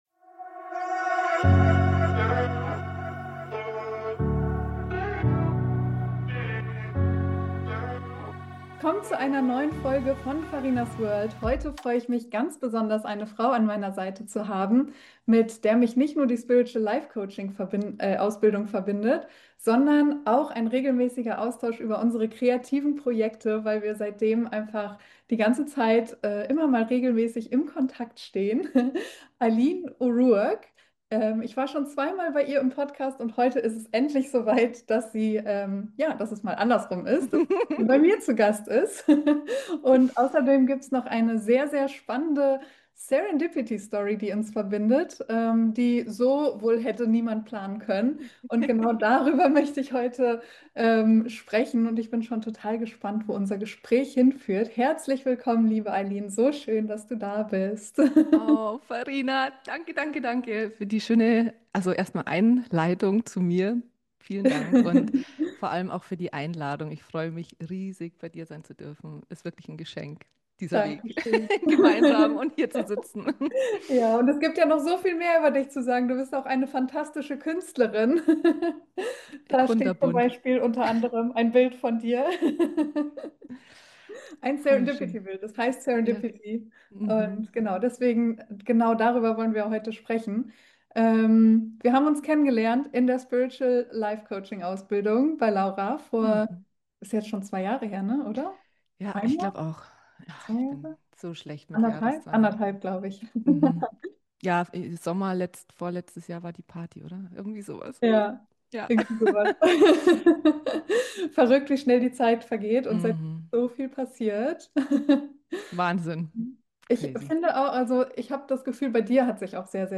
Der Tod verkauft sich nicht: ein Gespräch über das Leben